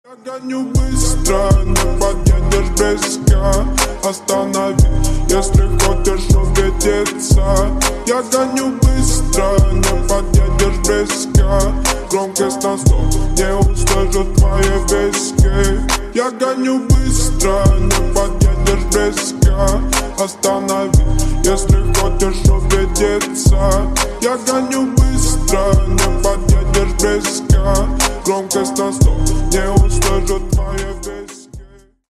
Рингтоны Ремиксы » # Рэп Хип-Хоп